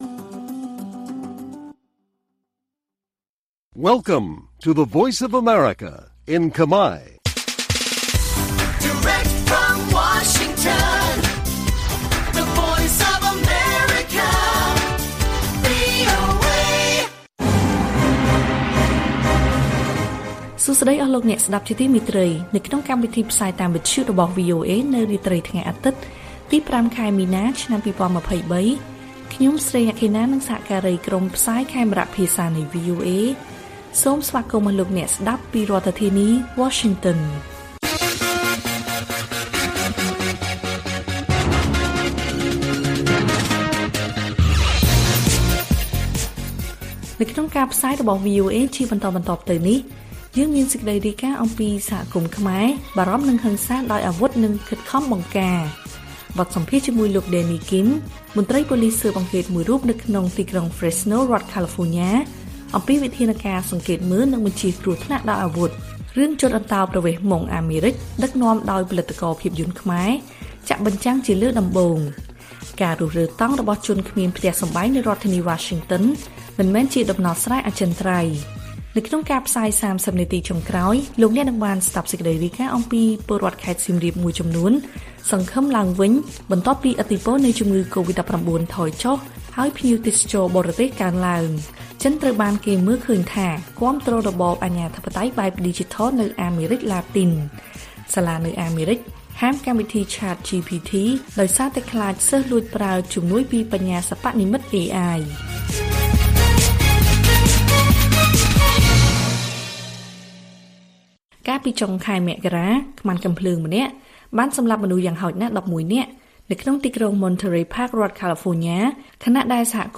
ព័ត៌មានពេលរាត្រី ៥ មីនា៖ សហគមន៍ខ្មែរបារម្ភនឹងហិង្សាដោយអាវុធនិងខិតខំបង្ការ